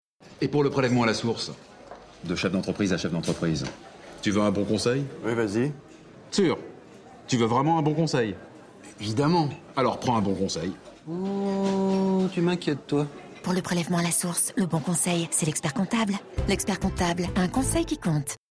le bon conseil // légère
Pub-Tv-Expert-comptablele-bon-conseil-legere.mp3